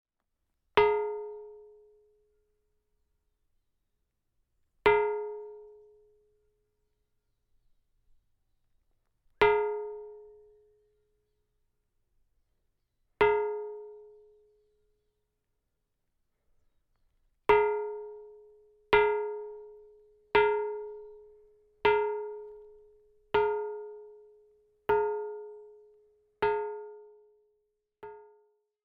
Syngesteinen ved Nedre Åmdal
Type: Klangstein
Lyden kan lett forveksles med ei kirkeklokke eller ei mindre stabbursklokke. Etterklangen er forbausende lang, og lyden blir slått tilbake fra åsen på andre siden av Espetjørna.
Frekvensen er den samme på hele steinen, men styrke og etterklang varierer litt.